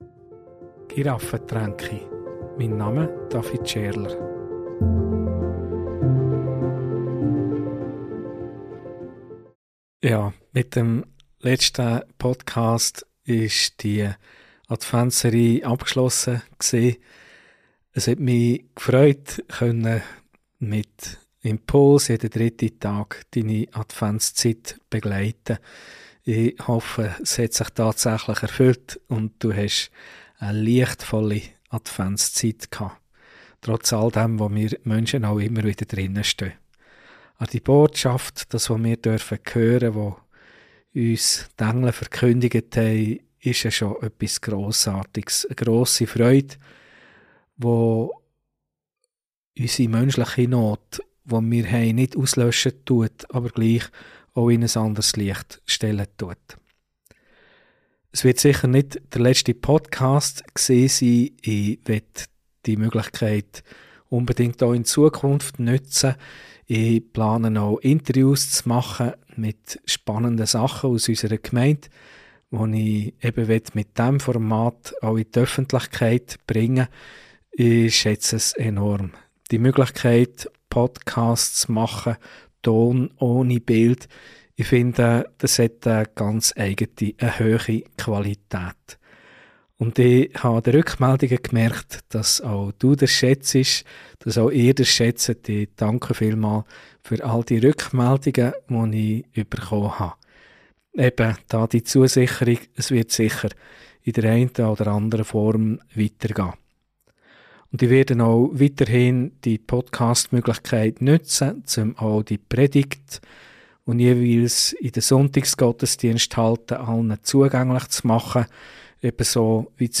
Predigt - Grosse Freude, das volle Leben!